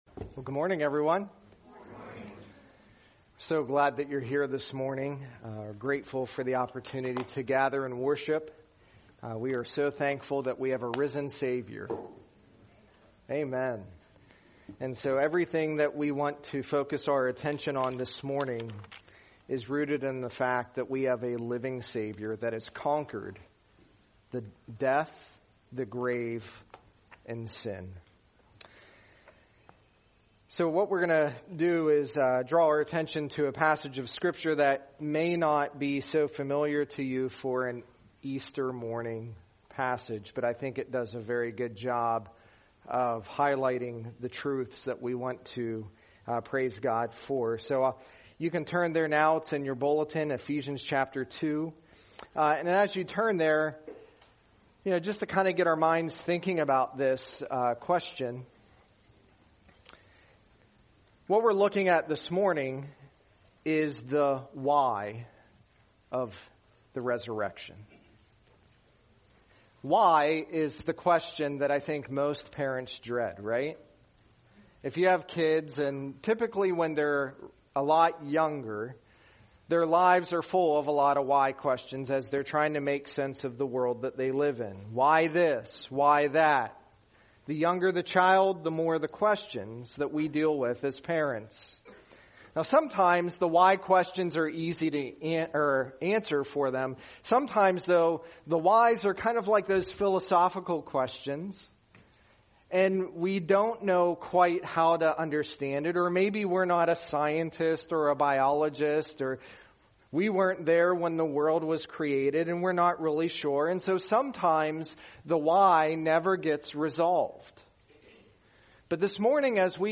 Sermons | North Annville Bible Church